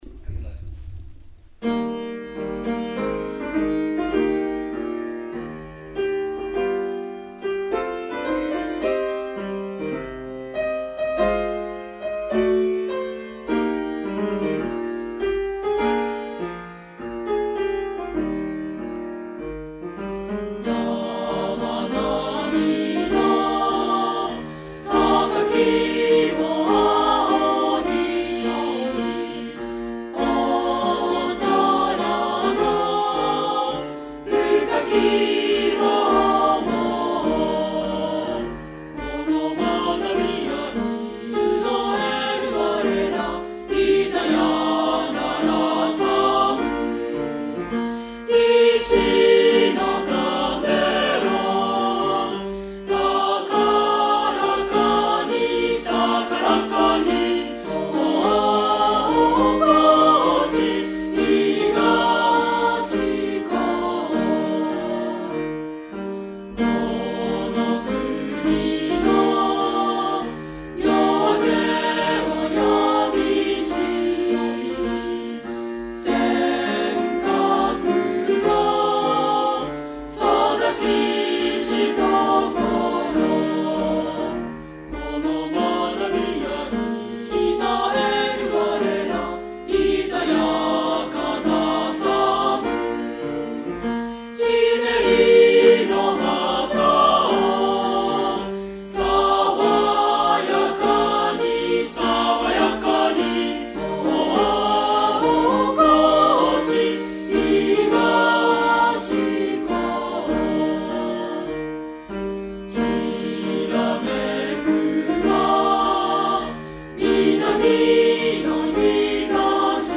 高知東高校_校歌.mp3